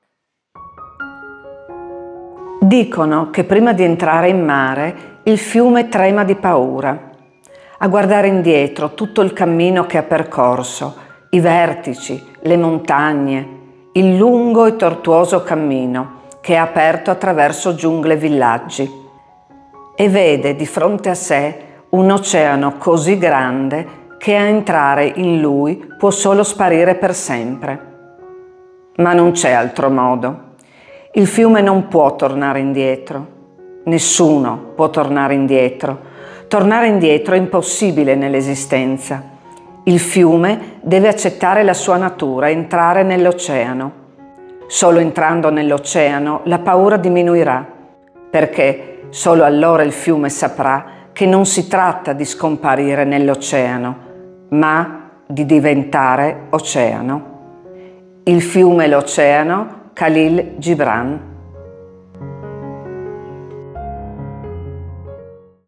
4_-_Poesia_Gibran-def.mp3